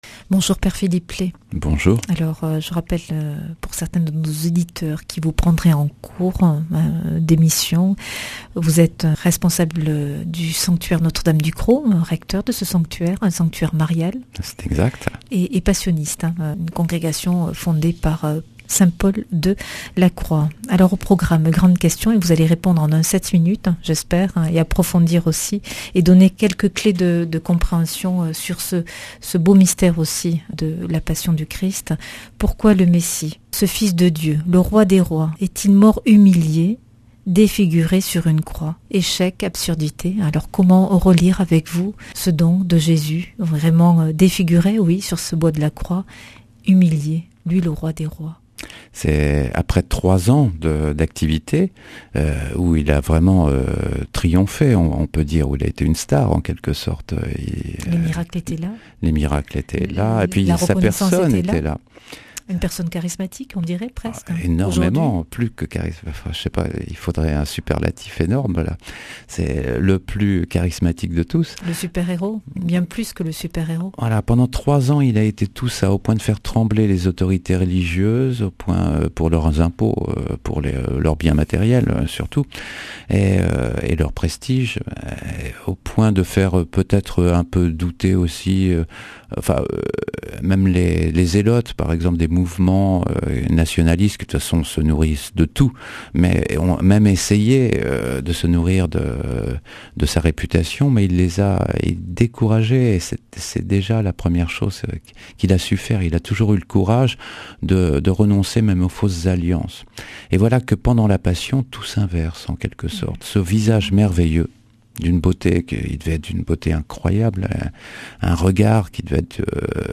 Speech
Une émission présentée par